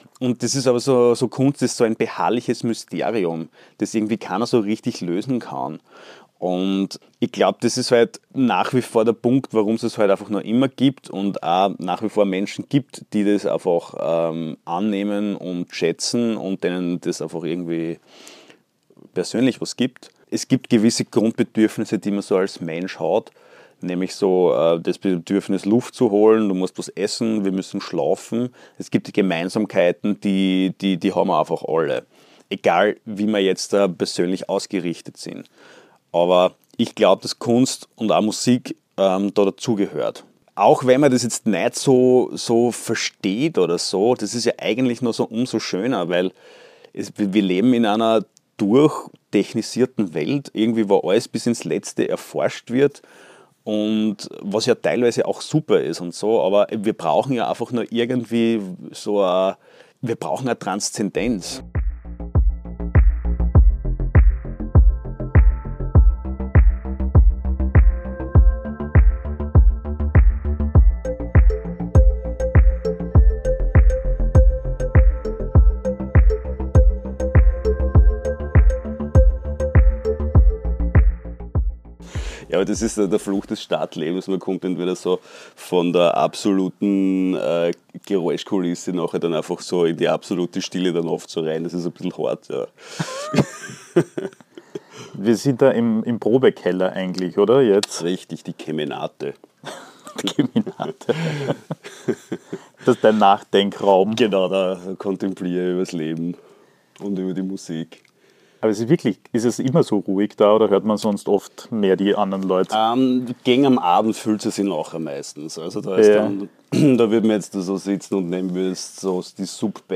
Ein Gespräch über Kummer und Frohsinn im Plural – und über Musik als Gegenmodell zur Gegenwart.